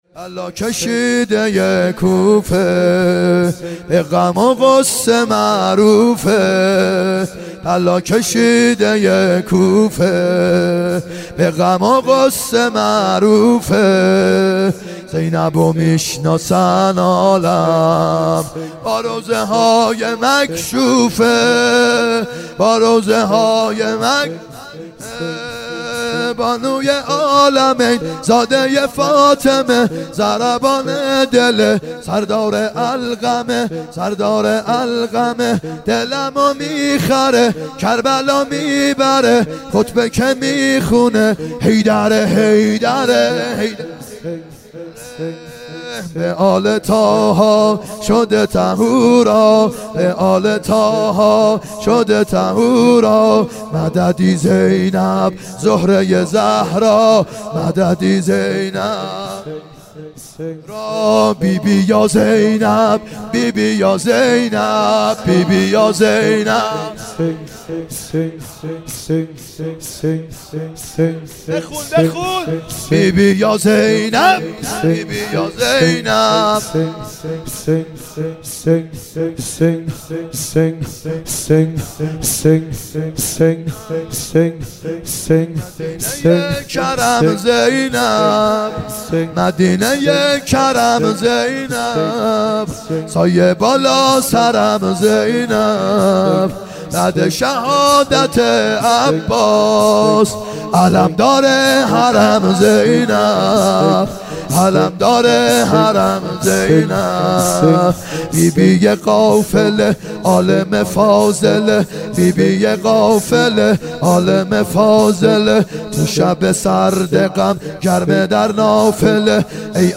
محرم 90 شب یازدهم شور ( بلا کشیده کوفه به غم غصه معروفه
محرم 90 ( هیأت یامهدی عج)